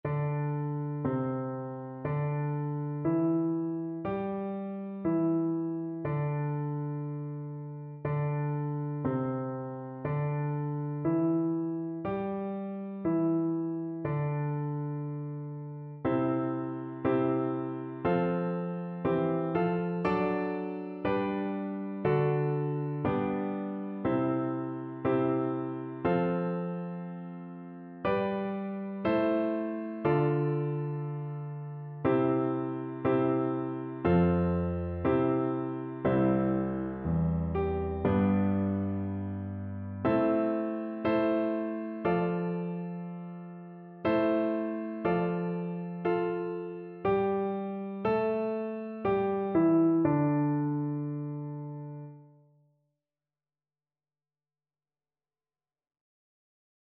C major (Sounding Pitch) (View more C major Music for Voice )
Slow
4/4 (View more 4/4 Music)
kimigayo_VOICE_kar1.mp3